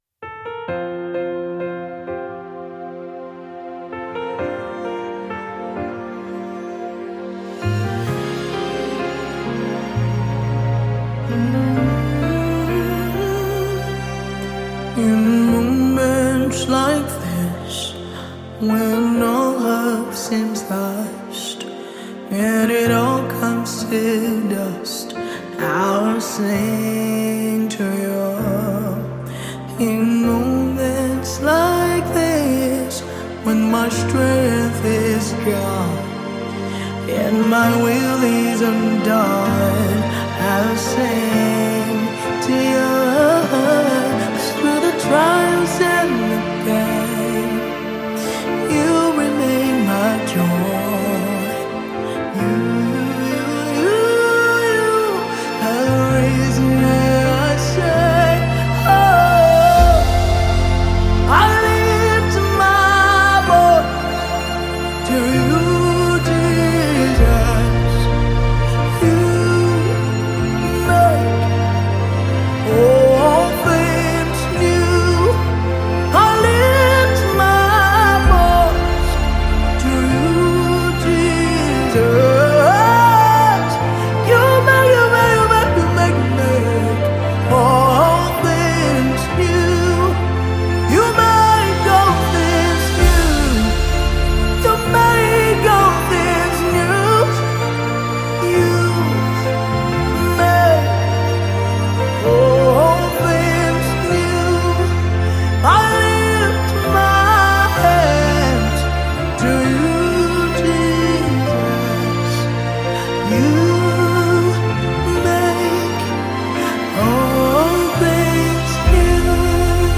GospelMusic